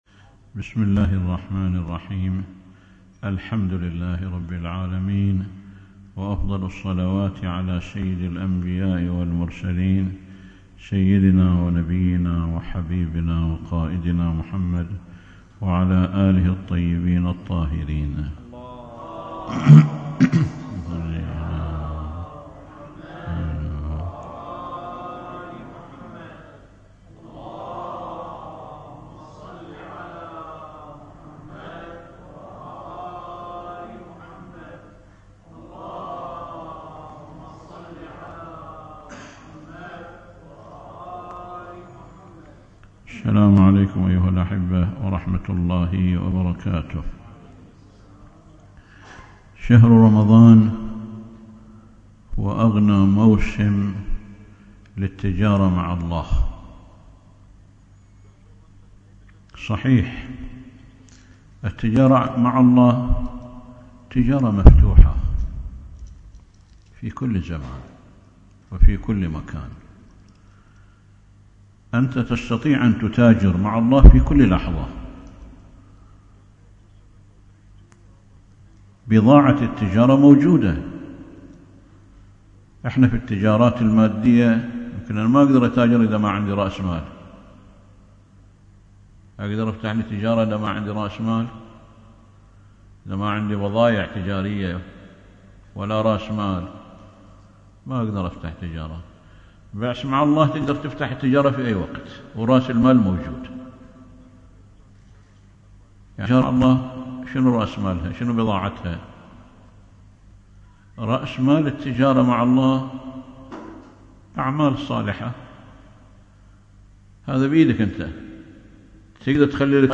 || مأتم الغربي || اللقاء المفتوح